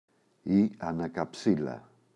ανακαψίλα, η [anaka’psila] – ΔΠΗ